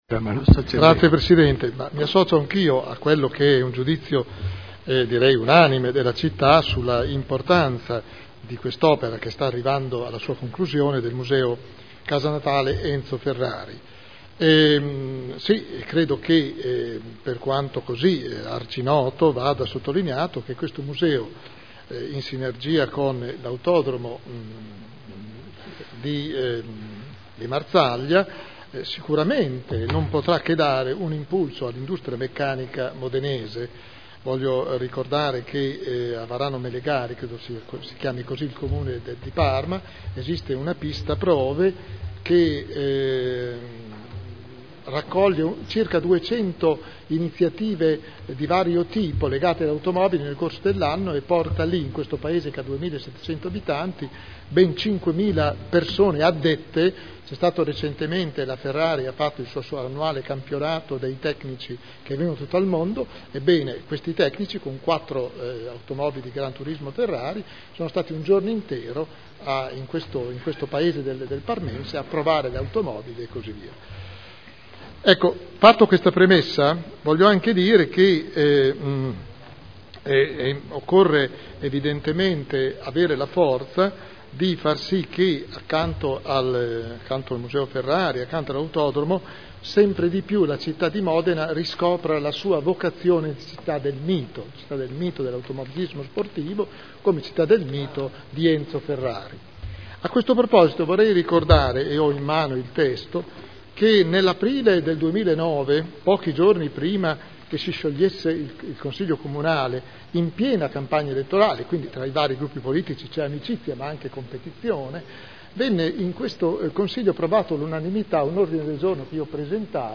Seduta del 09/01/2012. Dibattito su Interrogazione del consigliere Bellei (PdL) avente per oggetto: “Museo Enzo Ferrari” (presentata il 9 settembre 2011 - in trattazione il 9.1.2012) trasformata in interpellaznza su richiesta del Consigliere Celloni.